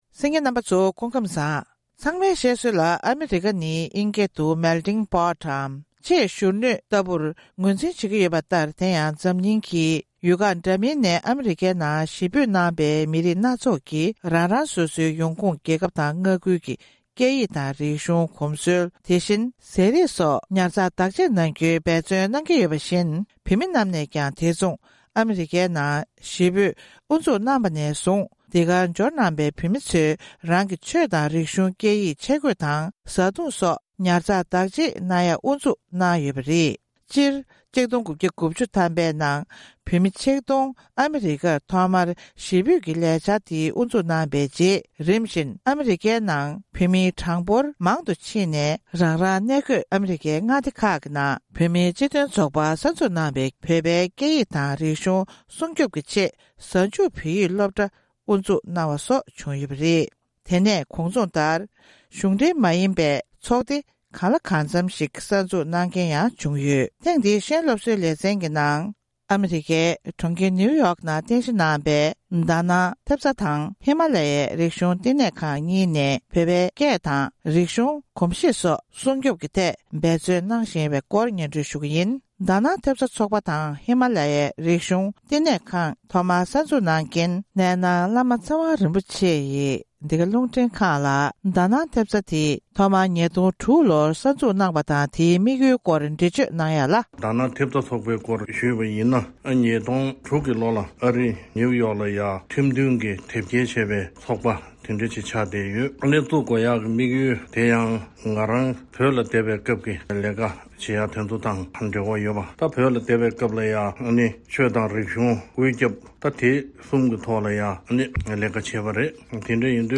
འབྲེལ་ཡོད་མི་སྣར་གནས་འདྲི་ཞུས་པ་ཞིག་གསན་གྱི་རེད།